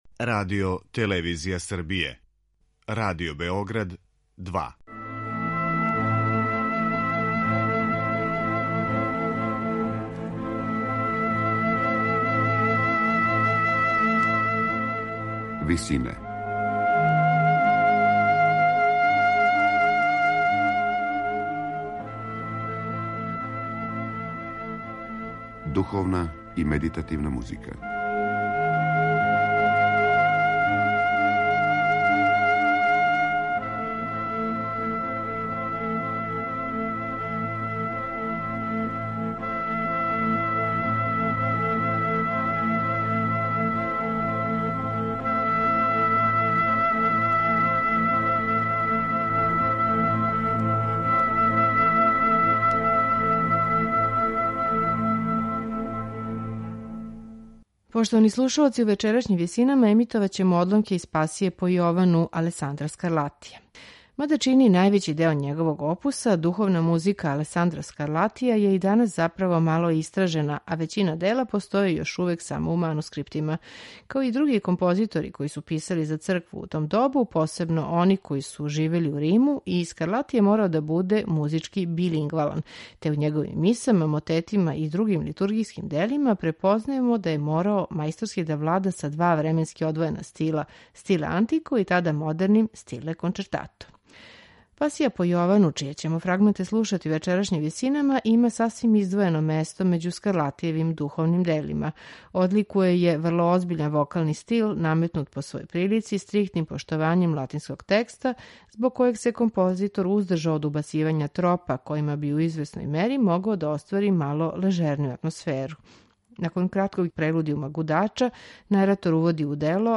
Рене Јакобс - наратор
хор Basel Madrigal, гудачки ансамбл Schola Cantorum Basisliensis
виолончело
харпсикорд и портативне оргуље
теорба